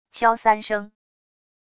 敲三声wav音频